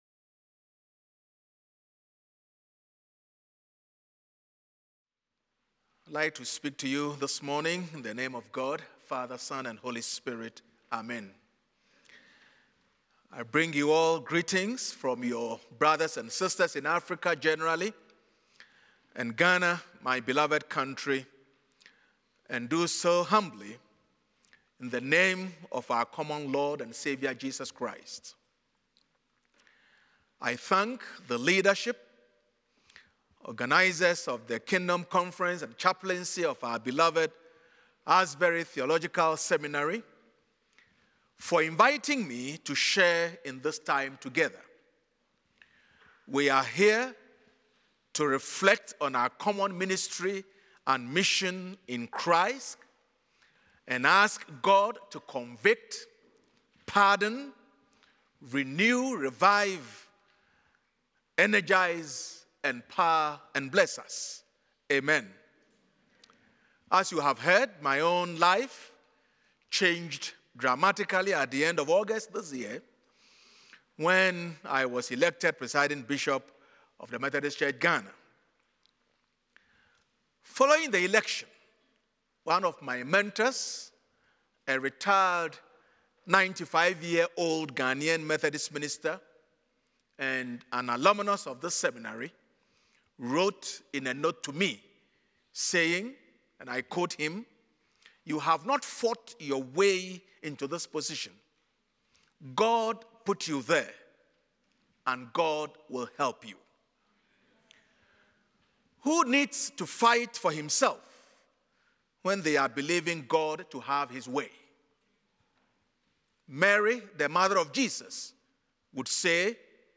The following service took place on Tuesday, October 15, 2024.